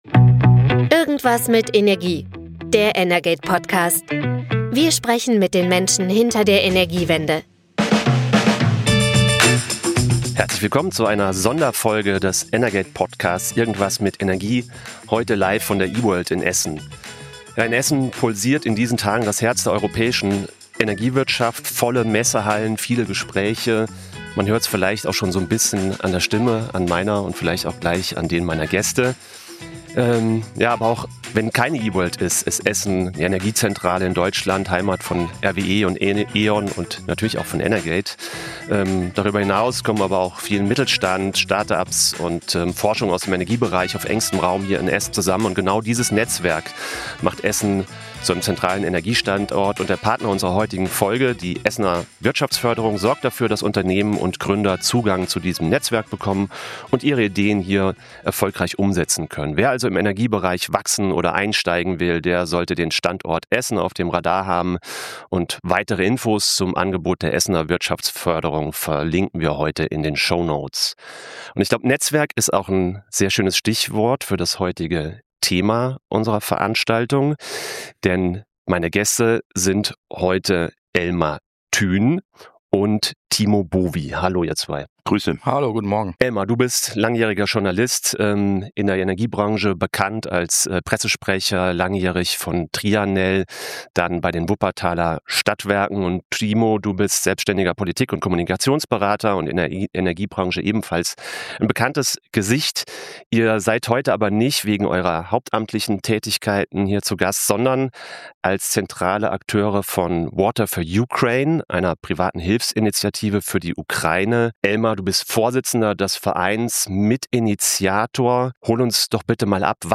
Anstatt eines regulären News-Updates kommt heute eine Sonderfolge des energate-Podcasts, den wir in dieser Woche live auf der Energiemesse E-world in Essen aufgezeichnet haben.